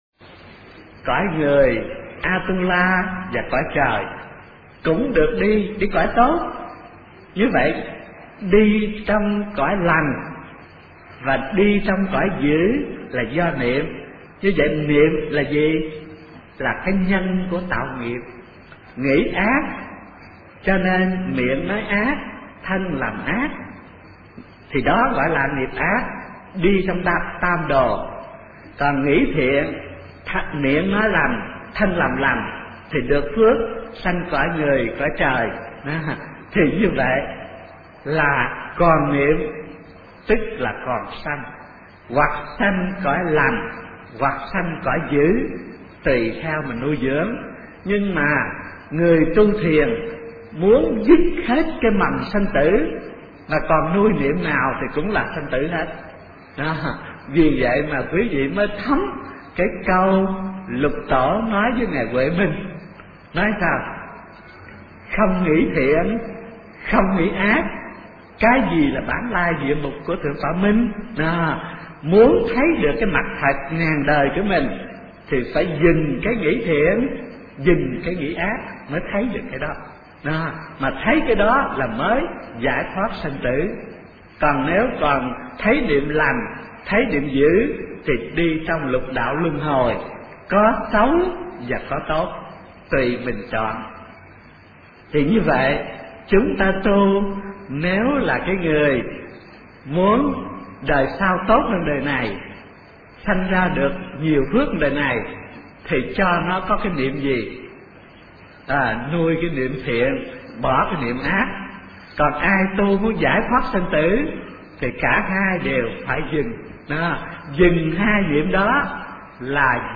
Kinh Giảng Thiền Tông Vĩnh Gia Tập - Thích Thanh Từ